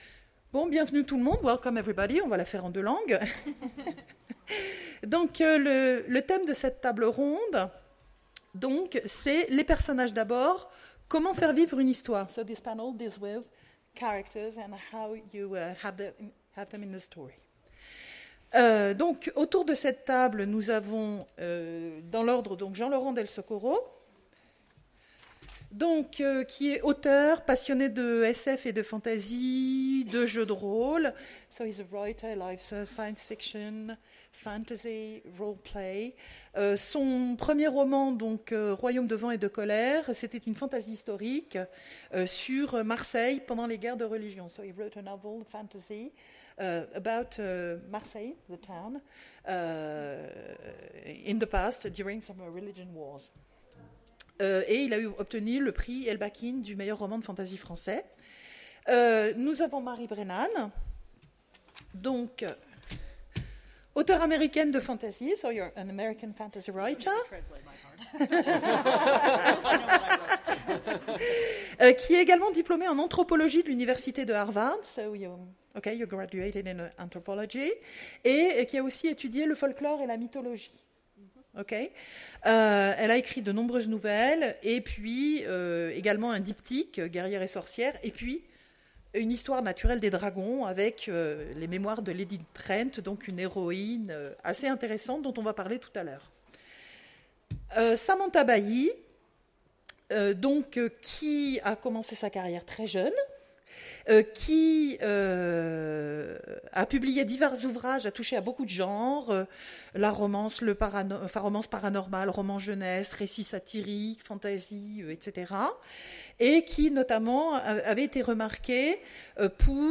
Imaginales 2016 : Conférence Les personnages d’abord !